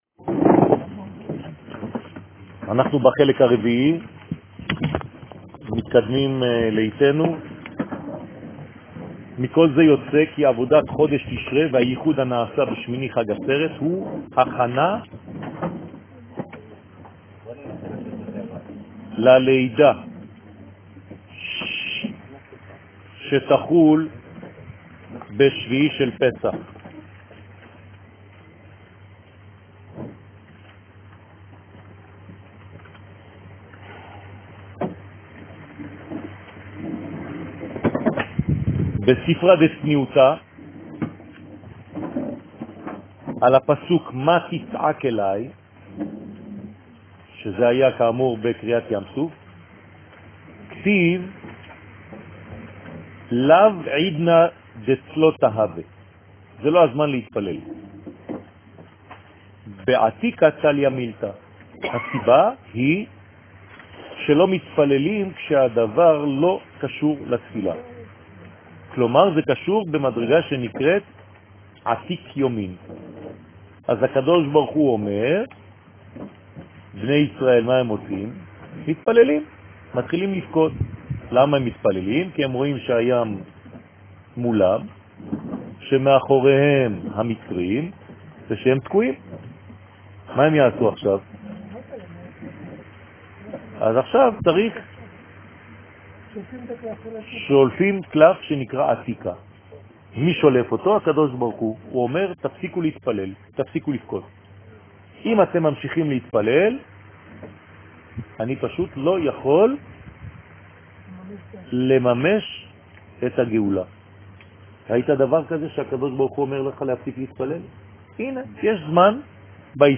הושענה רבה חלק ד חגים ראש חודש וחודשי שיעור מ 30 ספטמבר 2018 39MIN הורדה בקובץ אודיו MP3 (18.2 Mo) הורדה בקובץ אודיו M4A (4.65 Mo) TAGS : תורה ומועדים סוכות עברית שיעורים תורה וזהות הישראלי שיעורים קצרים